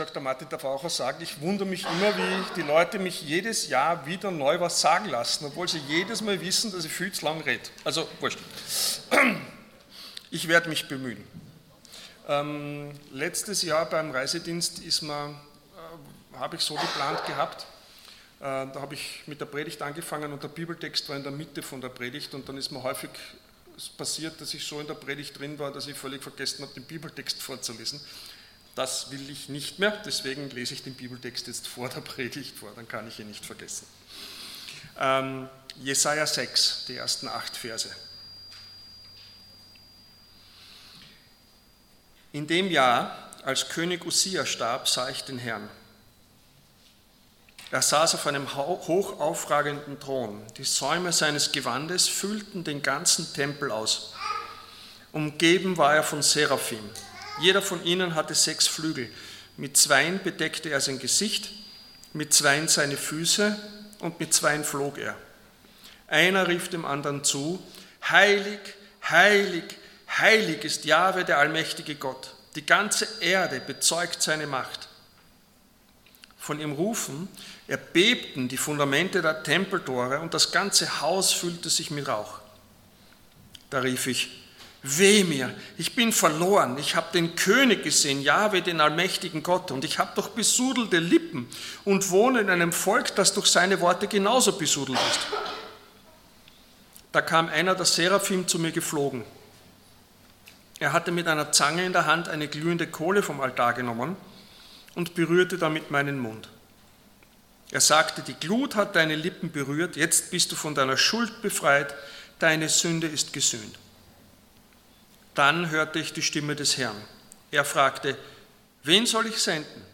Passage: Jesaja 6 Dienstart: Sonntag Morgen Unser soo großer Gott Themen